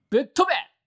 戦闘 掛け声 気合い ボイス 声素材 – Battle Cries Voice